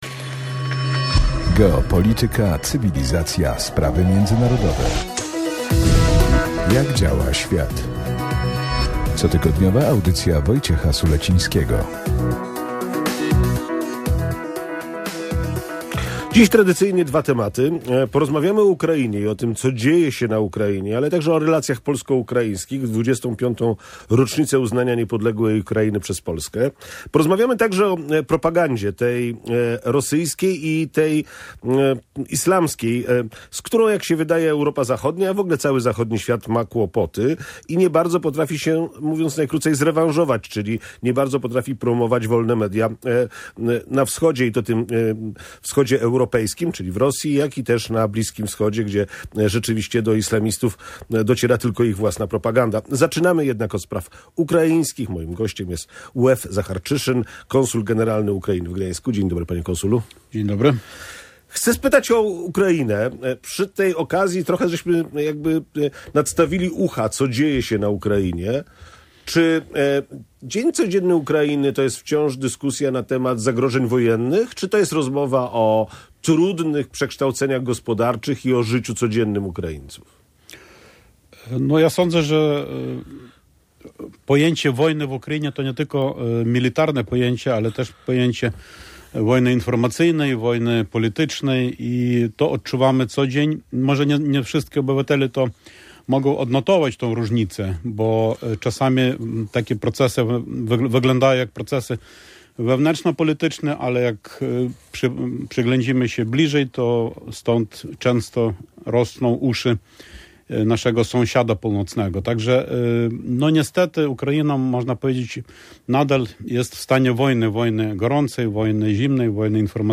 – Polityka polsko-ukraińska powinna być pragmatyczna, a nie emocjonalna – mówił w Radiu Gdańsk Łew Zacharczyszyn.